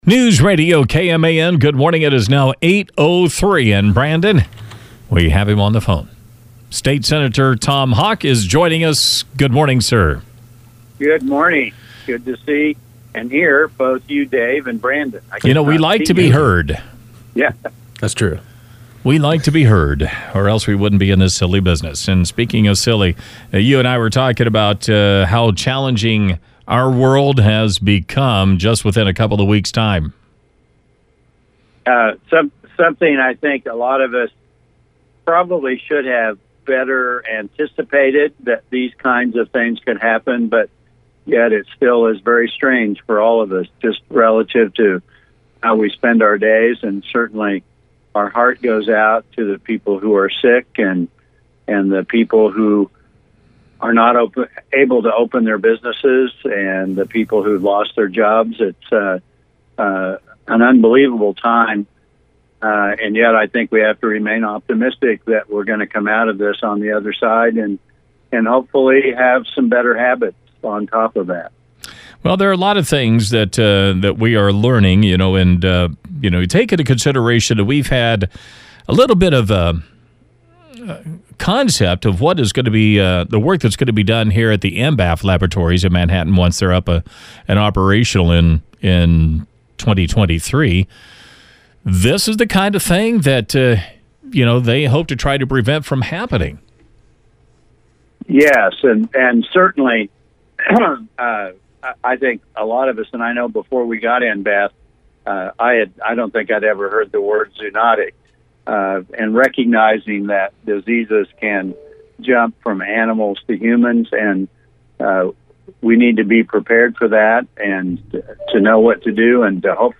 Senator Tom Hawk joined KMAN in the 8 a.m. hour.